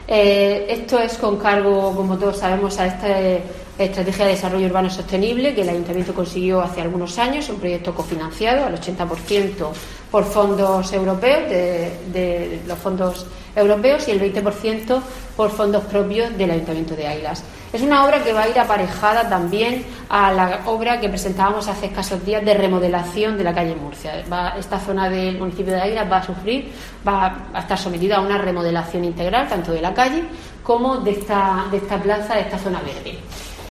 María del Carmen Moreno, alcaldesa de Águilas